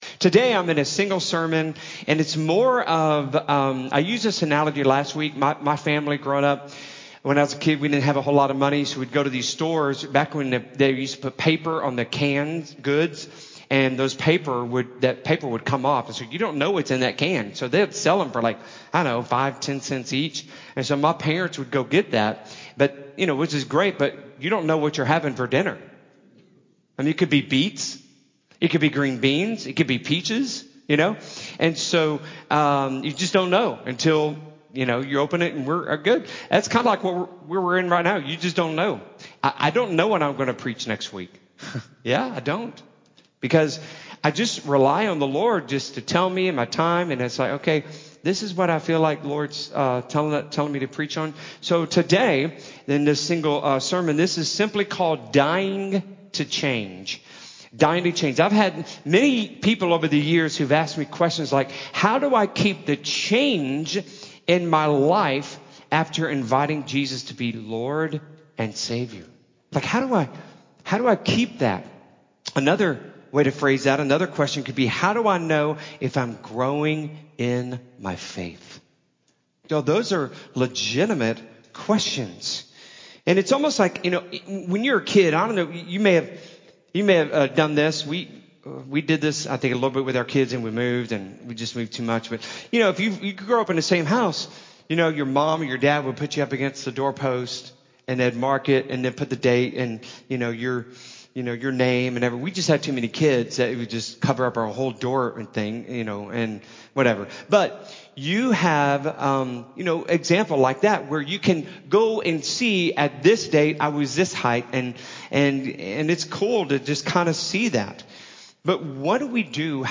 Sermon Audio
Dying-to-Change-Sermon-CD.mp3